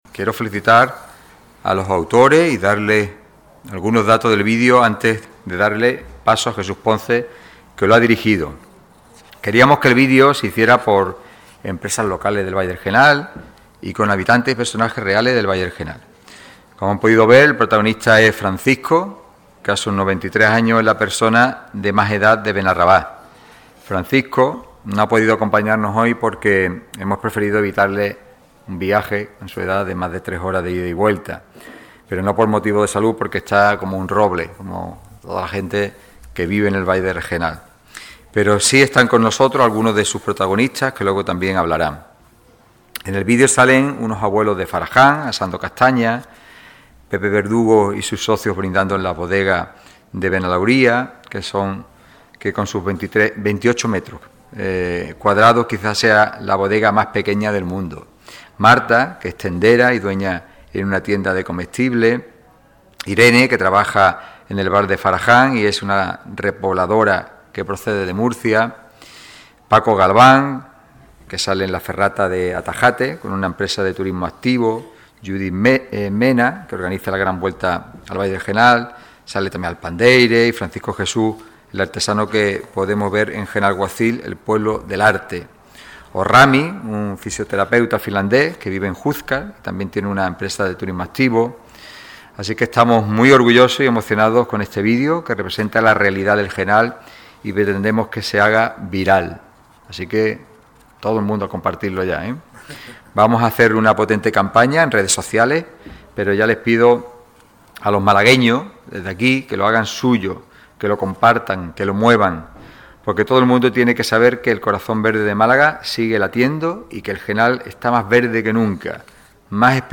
francisco-salado-ofrece-detalles-del-video.mp3